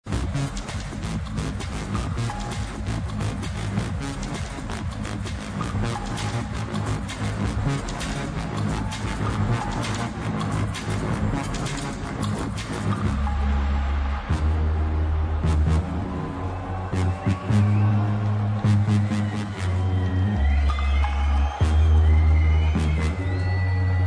(another) Awesome electro/tech tune
This tune rocked Space in Ibiza...